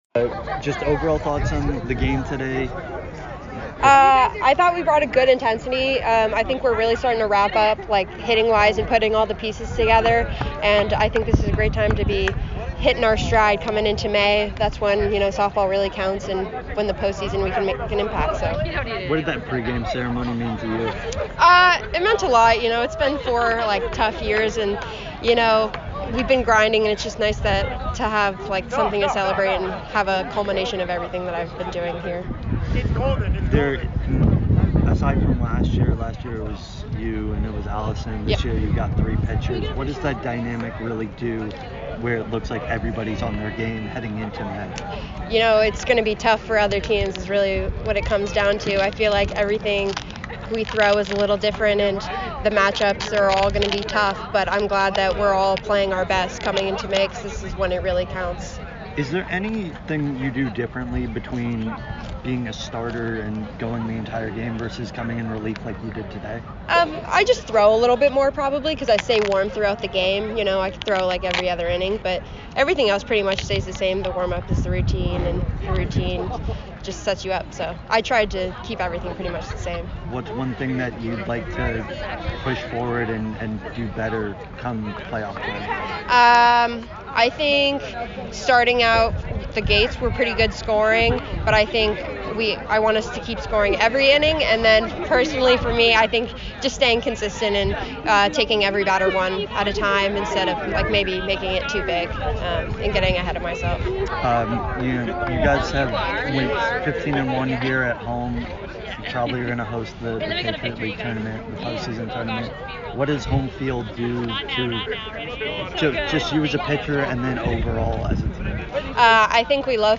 Army Postgame Interview